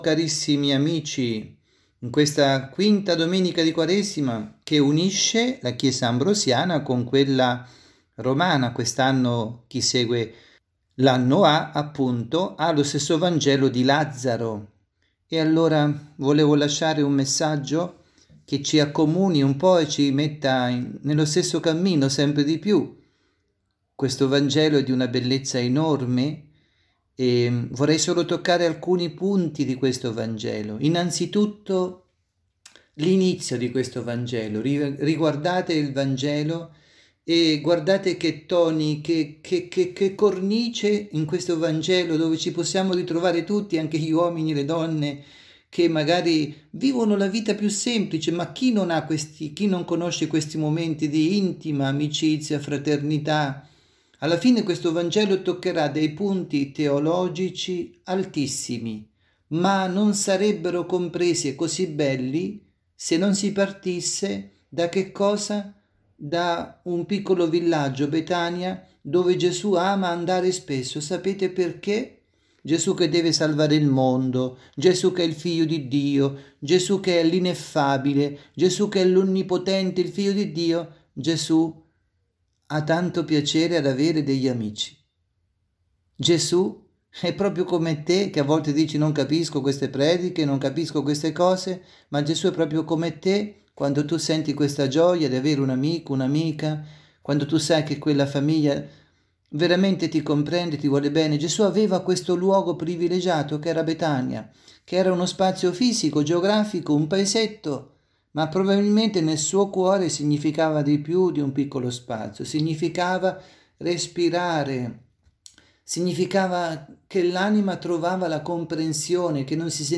Messaggio
dalla Parrocchia S. Rita – Milano – Vangelo del rito Ambrosiano e Romano – Giovanni 11, 1-53.